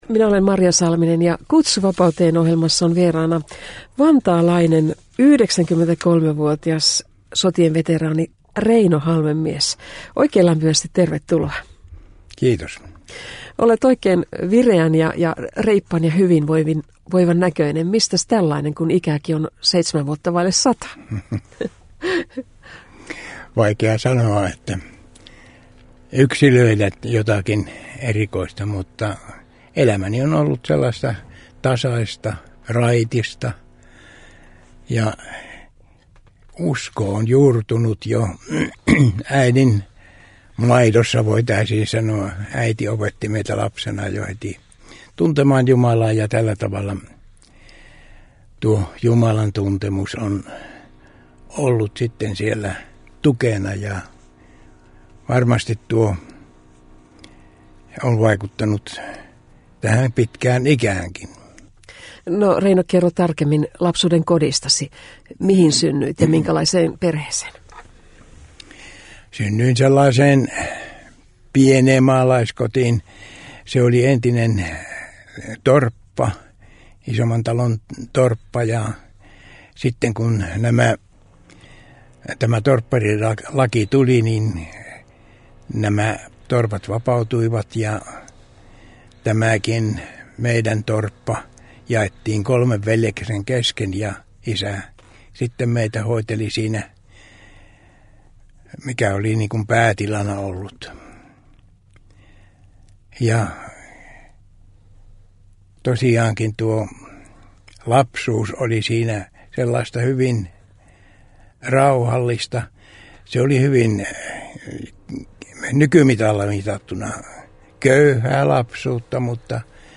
Kutsu vapauteen Radio Deissä la klo 20.03 ja uusinta ke klo 24.